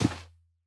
Minecraft Version Minecraft Version snapshot Latest Release | Latest Snapshot snapshot / assets / minecraft / sounds / mob / camel / step_sand2.ogg Compare With Compare With Latest Release | Latest Snapshot
step_sand2.ogg